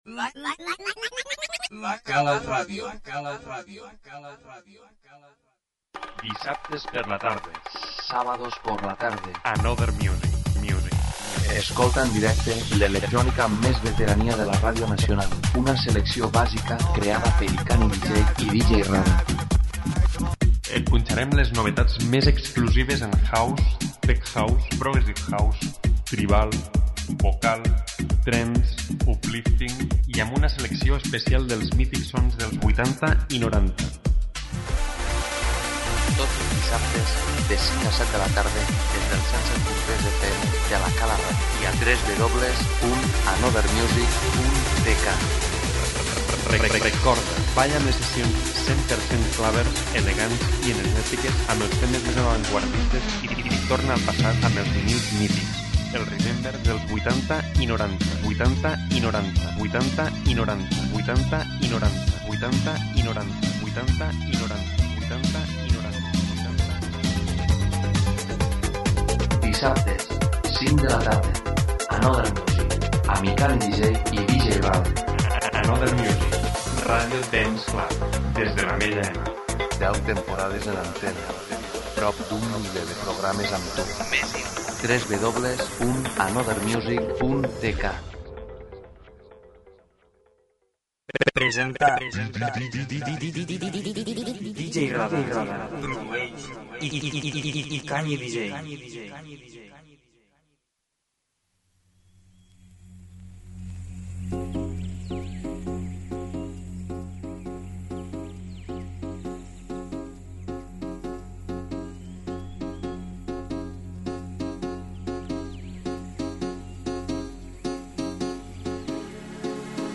presentem els sons House i Trance mes nous del mercat
luxe, elegància, energia, ritme..qualitat de club.